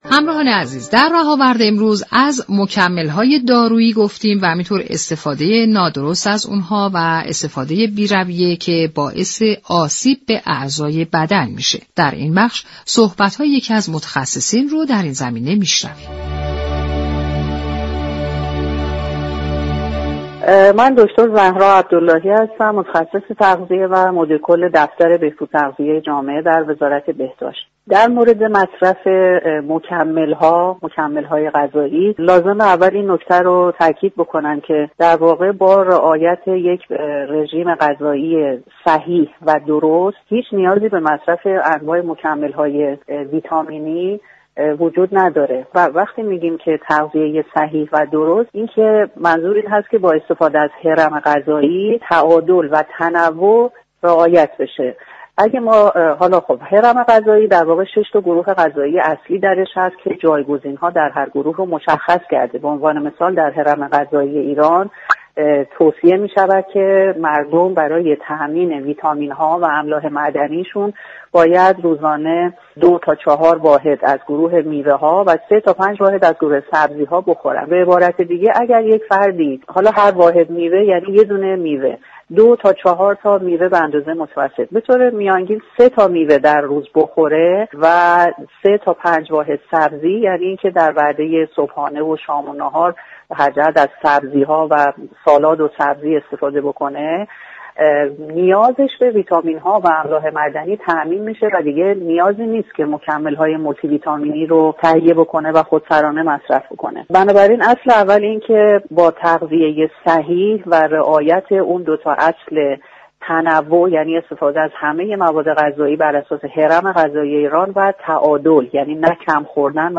در گفت و گو با برنامه «رهاورد»